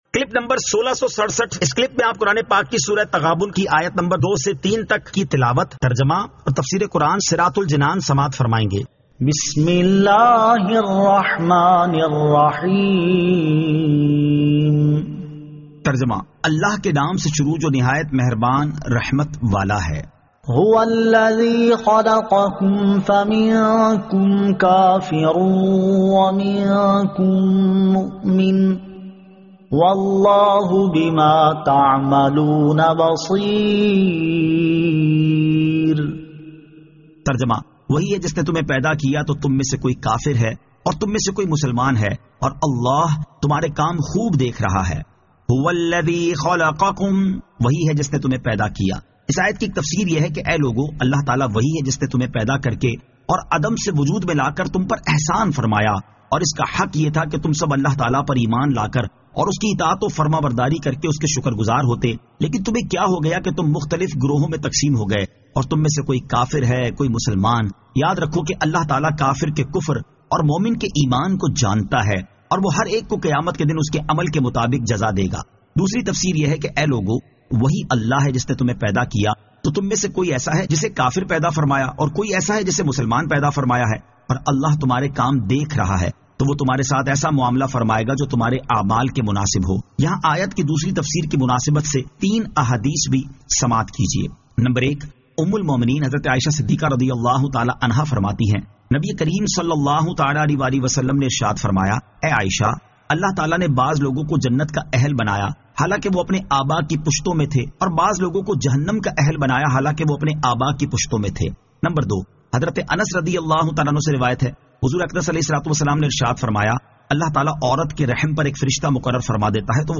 Surah At-Taghabun 02 To 03 Tilawat , Tarjama , Tafseer